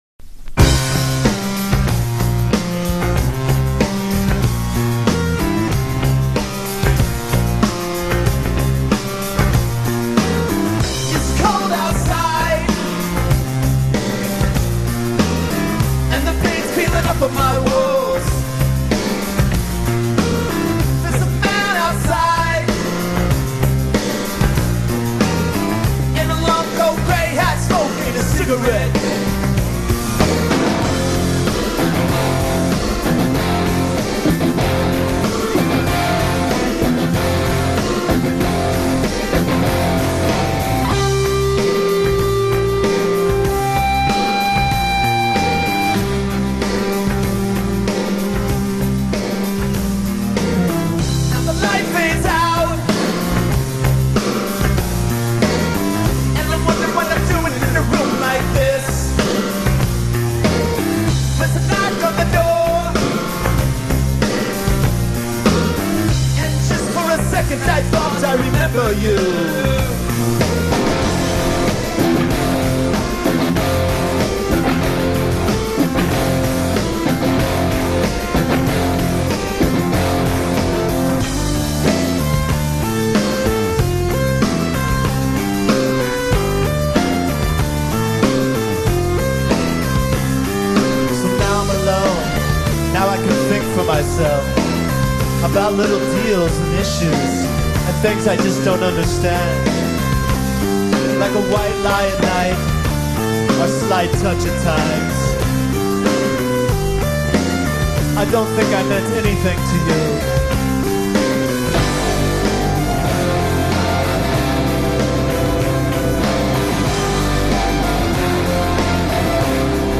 Adjective Battleship: Serious synth; feedback’s having fun.